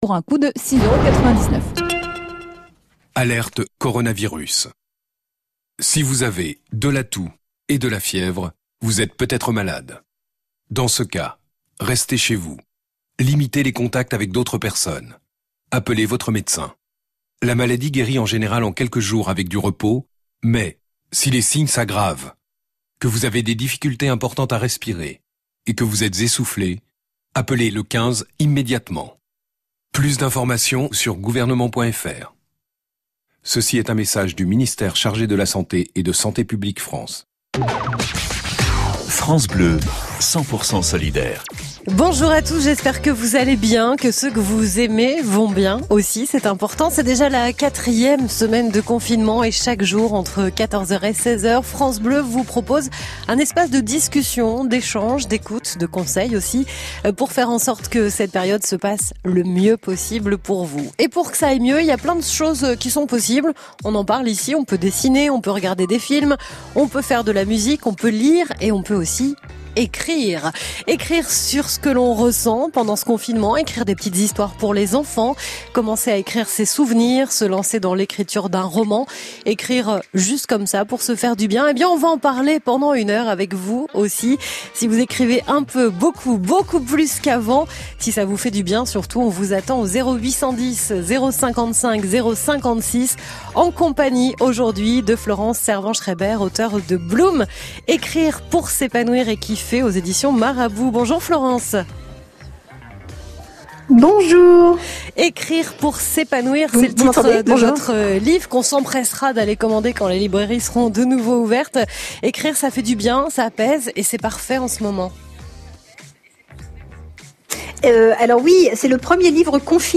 Une heure de musique, de conseils et d’écriture.